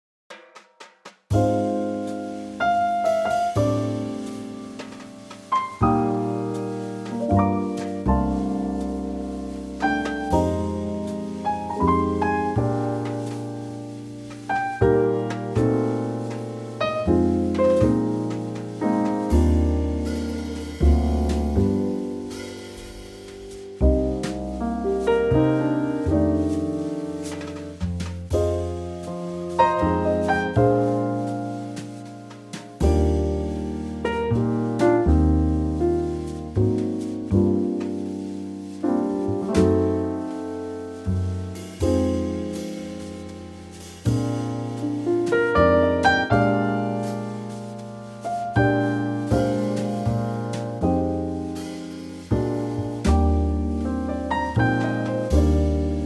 Unique Backing Tracks
key - Eb - vocal range - Bb to Eb
Beautiful old waltz, in a Trio arrangement.